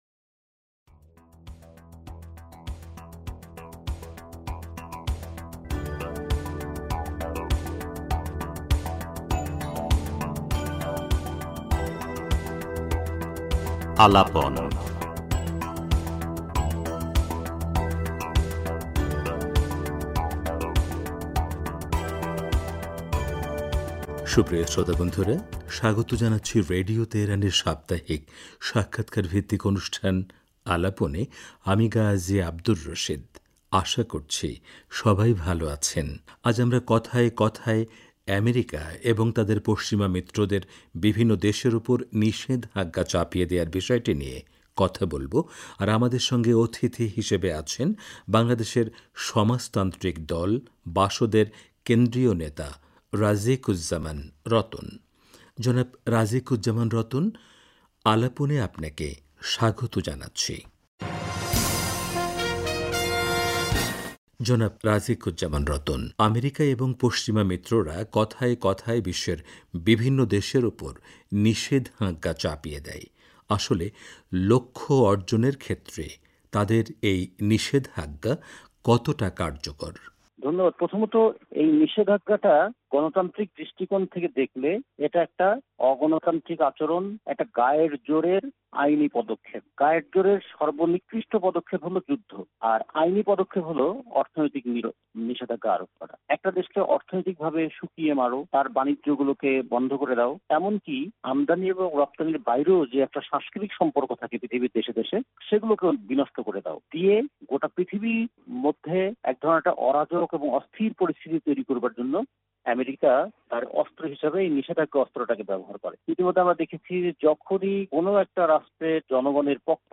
পুরো সাক্ষাৎকারটি তুলে ধরা হলো।